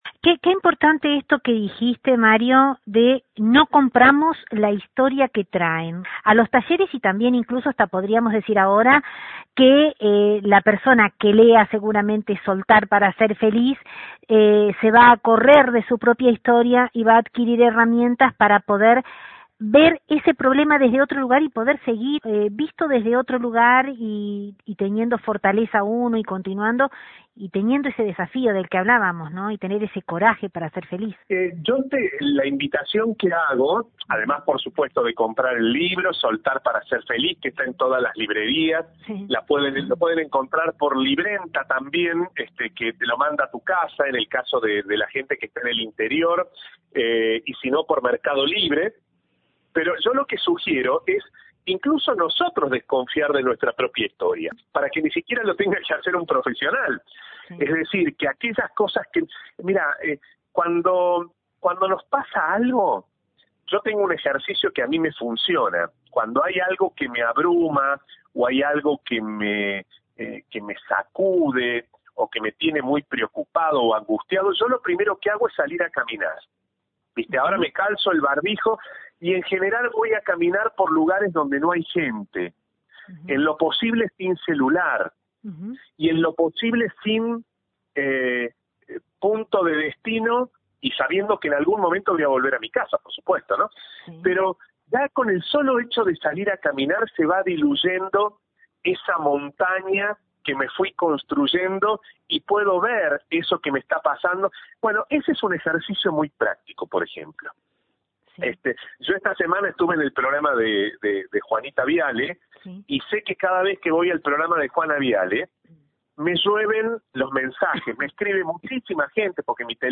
Luego en el transcurso de la entrevista, se detuvo en una tríada, que conforman los miedos que mayormente están instalados en los corazones de las personas, que acuden y participan en sus talleres: